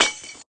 glassshatter.ogg